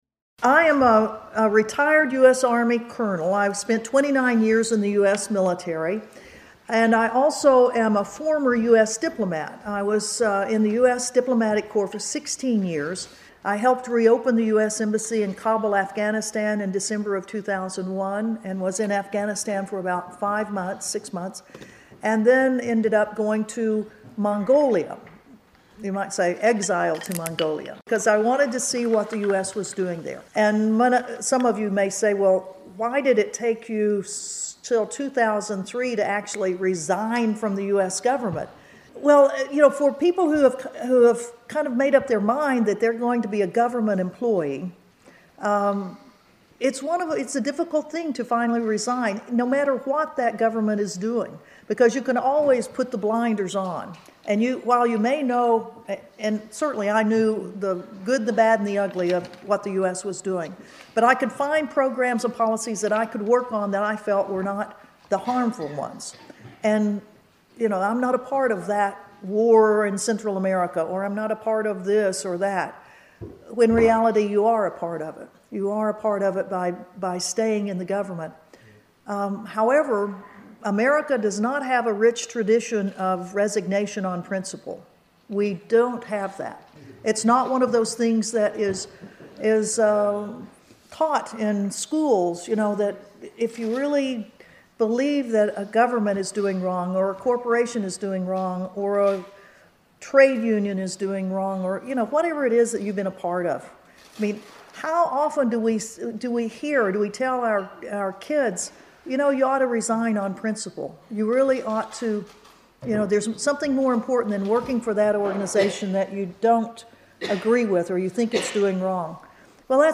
Col Ann Wright highest ranking US officer to resign in protest over the invasion of Iraq addresses public meeting in Dublin
Col Ann Wright was in Dublin this week to address a public meeting organised by the Irish Anti-War Movement and the Peace and Neutrality Alliance on St. Valentine’s Day -Thursday, 14 Feb., 2013. Col Wright resigned from the US military in 2003 in protest over the invasion of Iraq after 29 years in government service in various army and diplomatic postings.